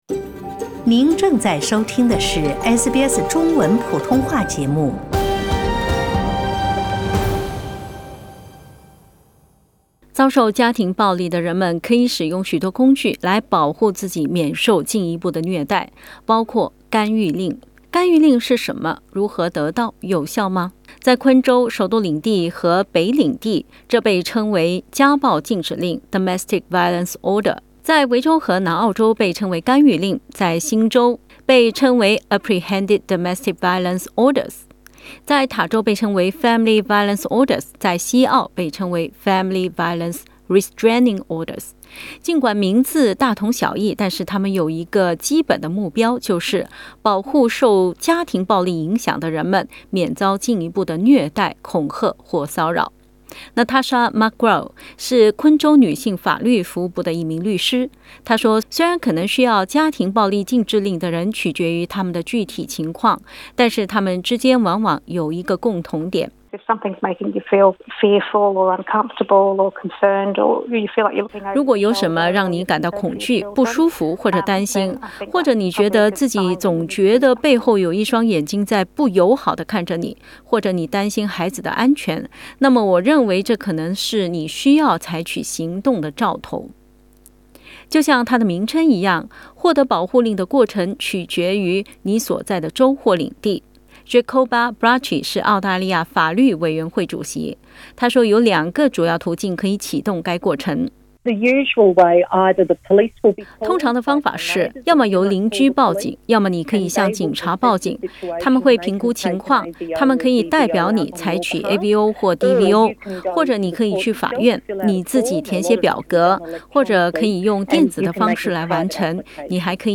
（请听报道） 澳大利亚人必须与他人保持至少1.5米的社交距离，请查看您所在州或领地的最新社交限制措施。